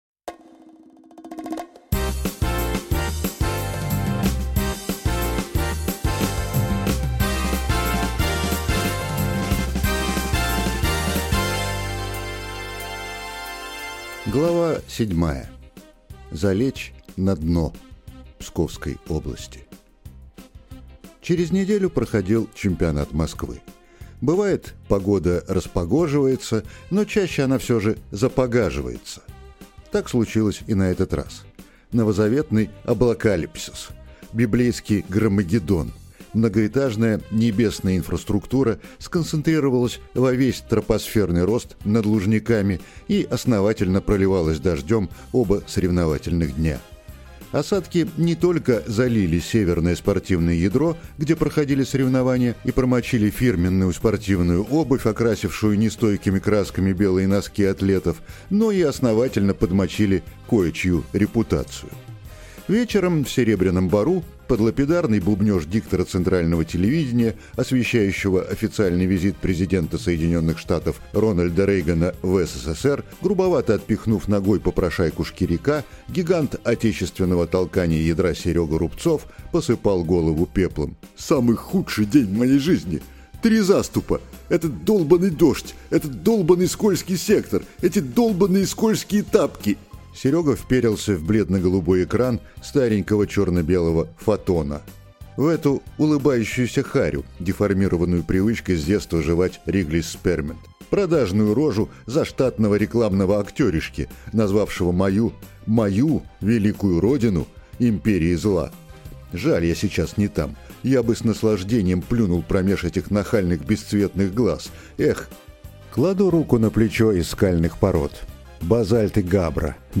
Аудиокнига Внимание… Марш!